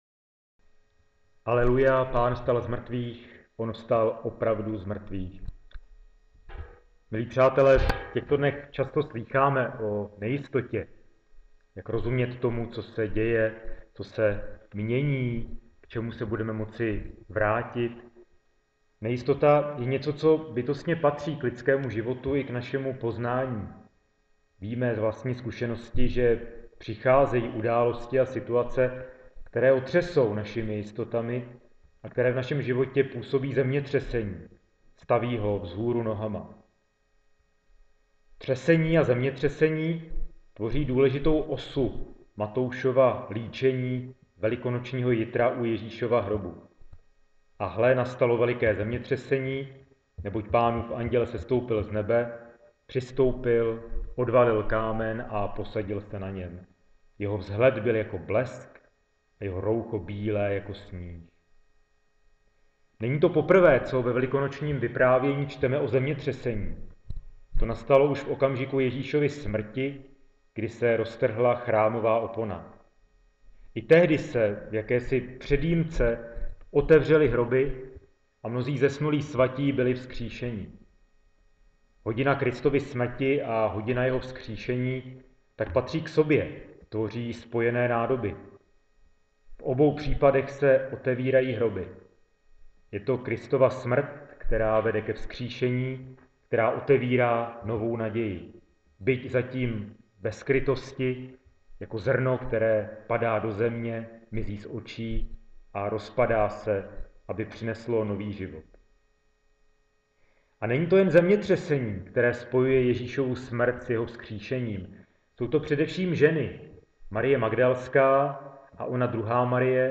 kazani Mt 28.wma